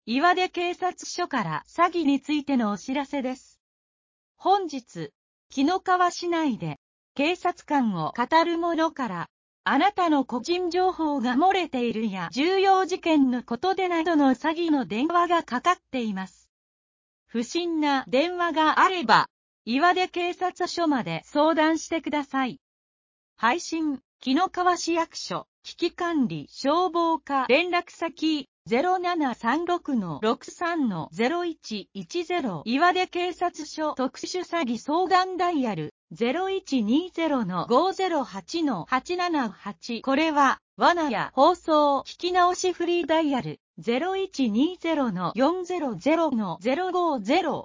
紀の川市メール配信サービス 【防災行政無線情報】